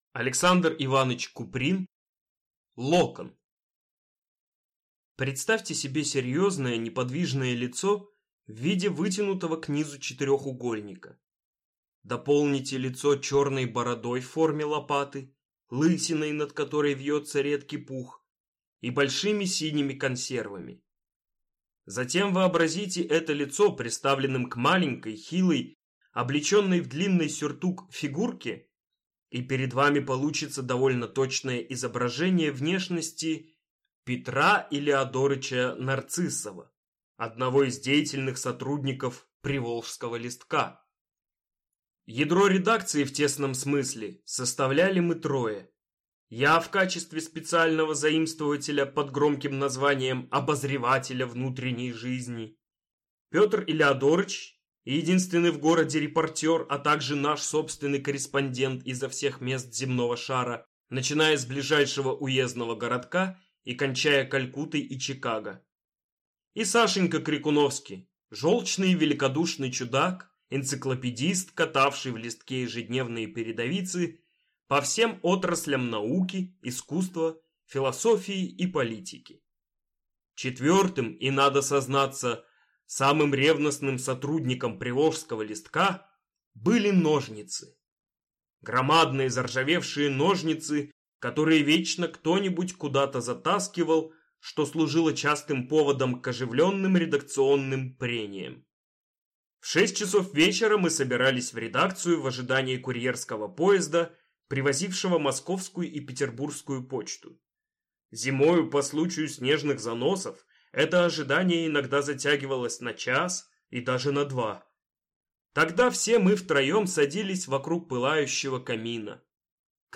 Аудиокнига Локон | Библиотека аудиокниг